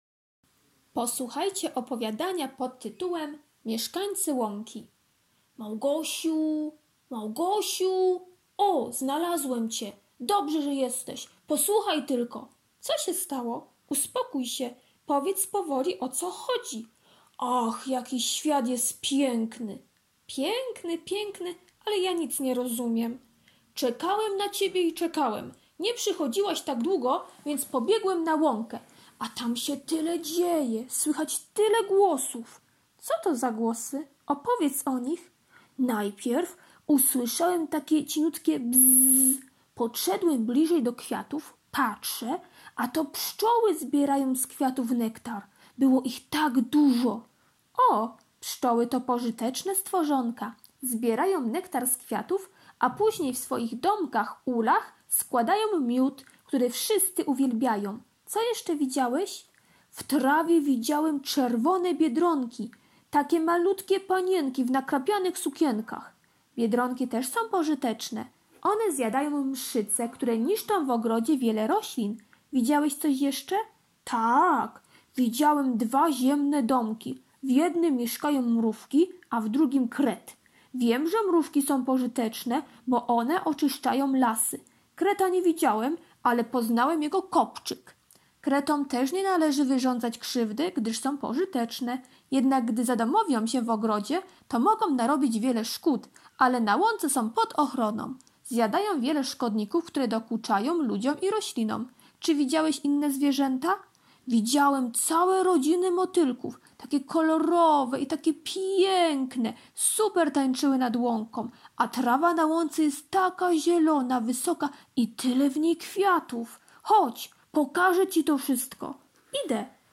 wtorek - opowiadanie "Mieszkańcy łąki" [3.89 MB] wtorek - prezentacja [2.20 MB] wtorek - karta pracy nr 1 - pisanie sylab i wyrazów [207.74 kB] wtorek - ćw. dla chętnych - kolorowanka wg kodu "Łąka" [150.00 kB] wtorek - ćw. dla chętnych - malowanie paluszkami "Łąka" [404.83 kB] wtorek - ćw. dla chętnych "Motyl" [199.73 kB]